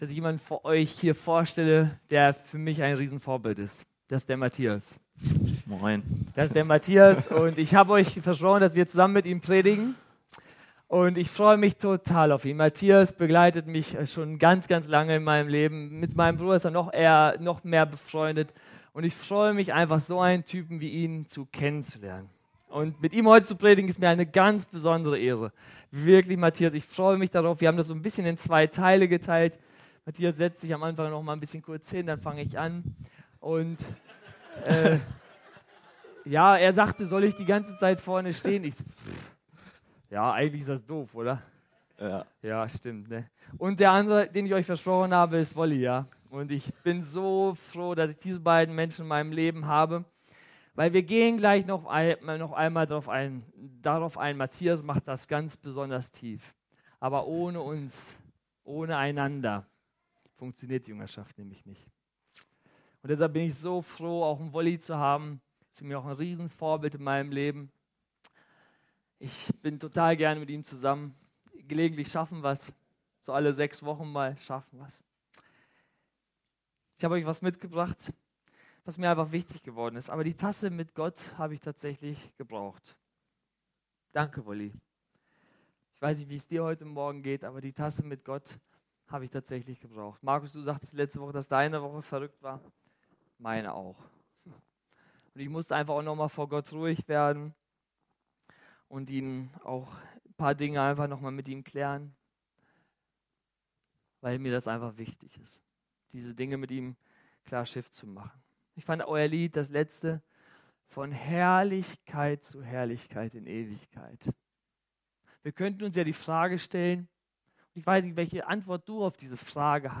Predigt vom 31.
Gottesdienst